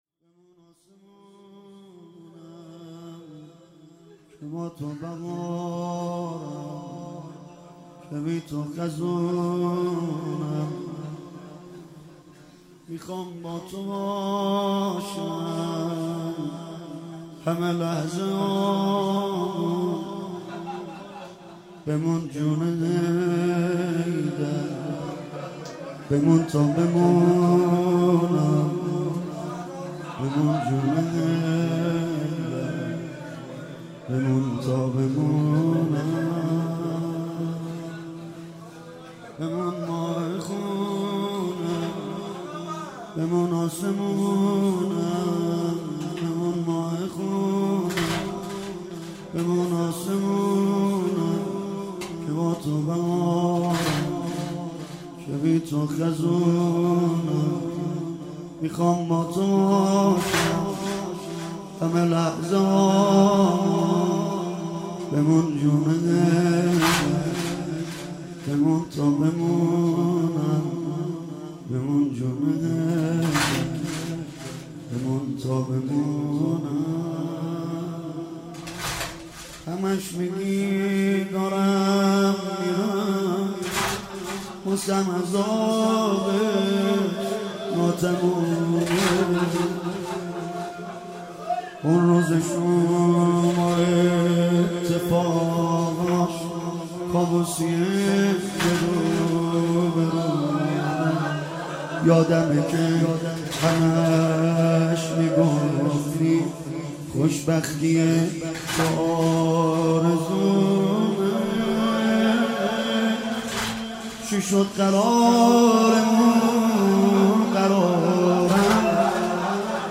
11جمادی الاول 95 - واحد - بمون آسمونم که باتو بهارم